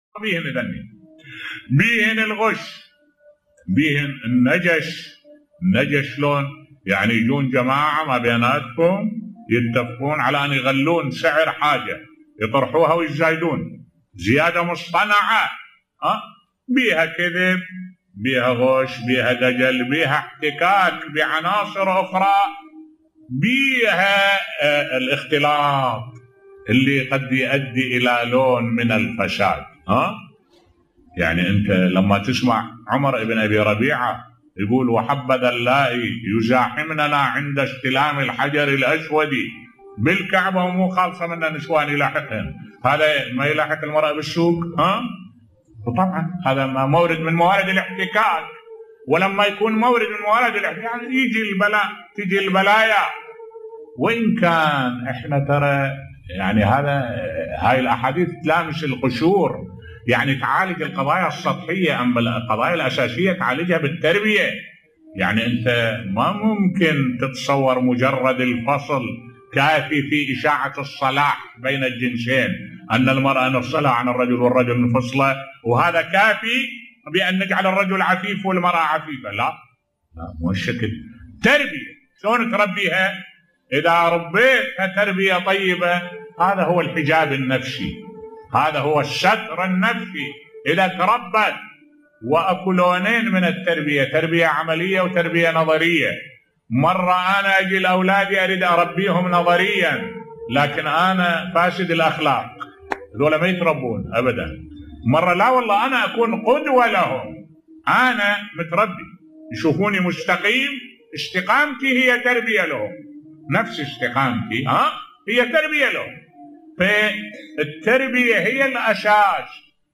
ملف صوتی العامل الاساسي في التربية بصوت الشيخ الدكتور أحمد الوائلي